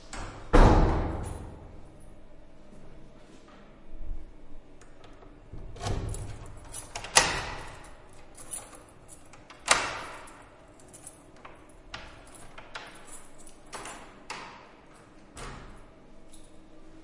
钥匙开锁
描述：将钥匙插入锁内并解锁。
Tag: 正面 按键 锁定 解锁 解锁